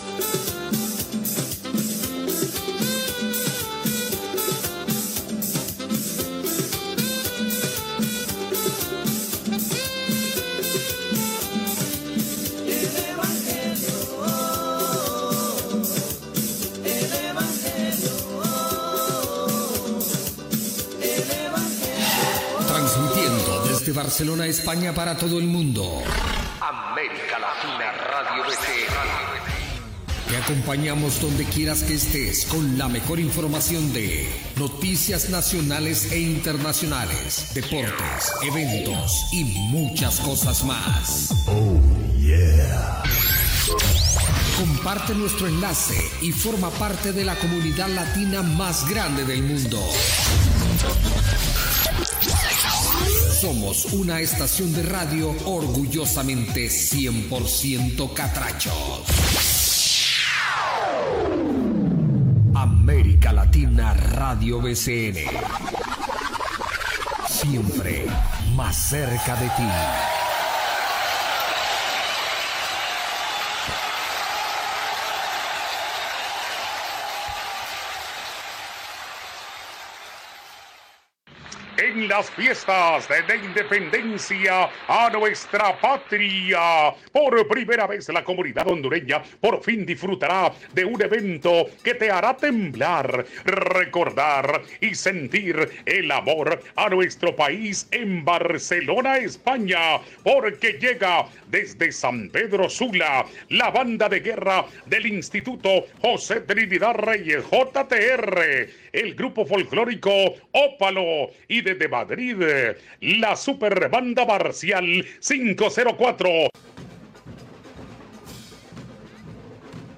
Indicatiu de la ràdio, anunci de la Fiesta Hondureña a l'estadi Pere Gol de Barcelona, salutació, publicitat, partits de futbol amateur Cosmos-Catrachos i Guerreros-Villanueva, Festa d'Hondures, indicatiu del programa i tema musical Gènere radiofònic Entreteniment